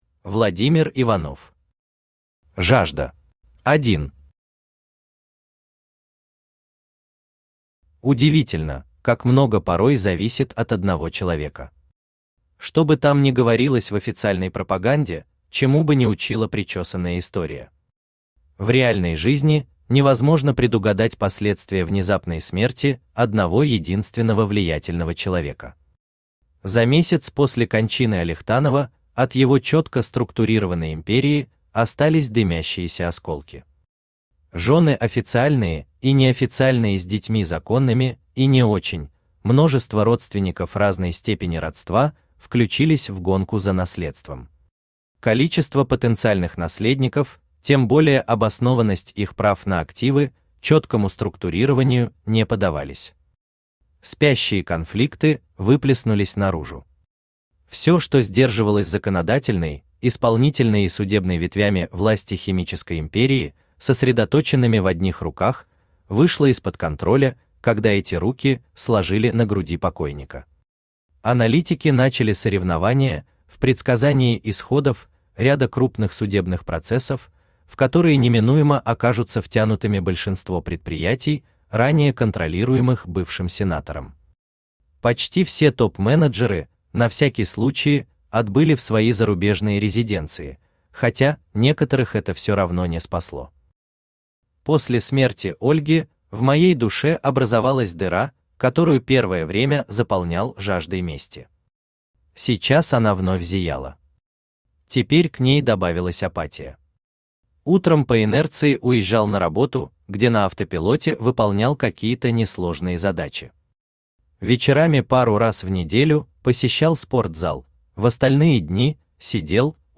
Аудиокнига Жажда…